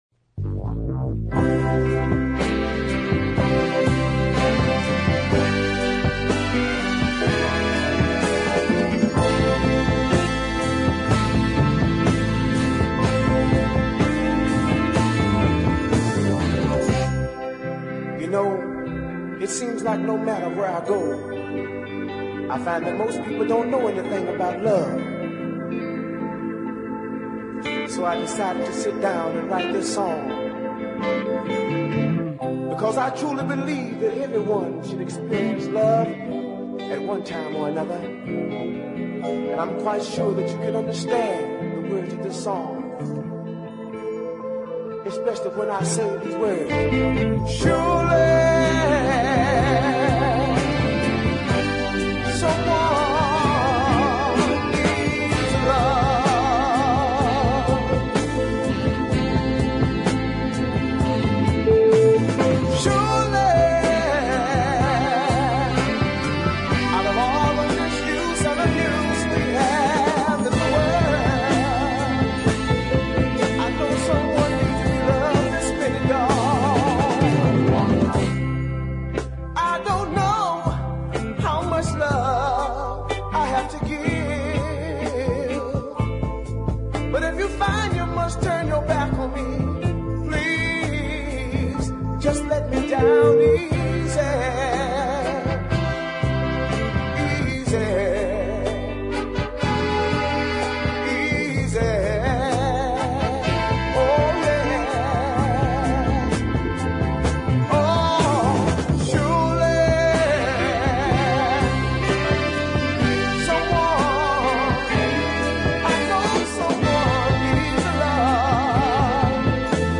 first class deep ballad